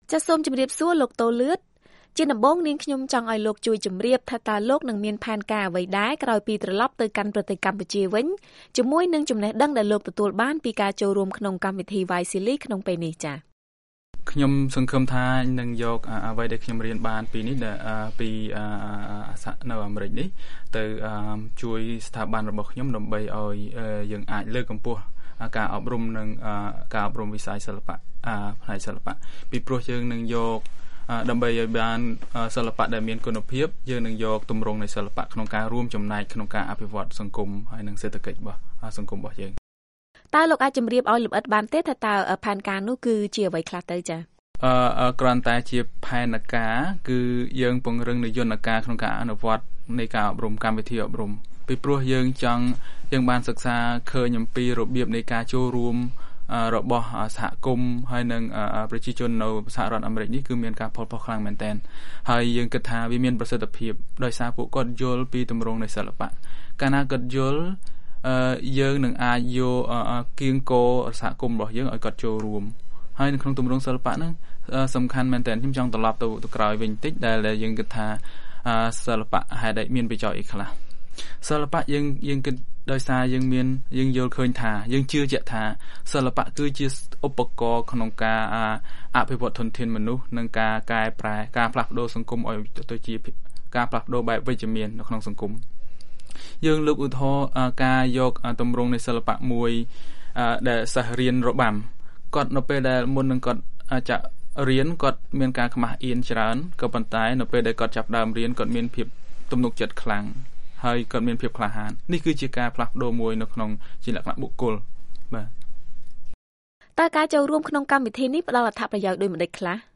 បទសម្ភាសន៍ VOA៖ ការប្រើប្រាស់សិល្បៈជាឧបករណ៍សម្រាប់ការផ្លាស់ប្ដូរវិជ្ជមាននៅក្នុងសង្គម